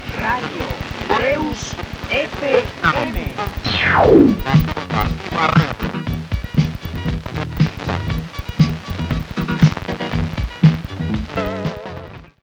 Indicatiu de l'emissora i tema musical.
Qualitat del so deficient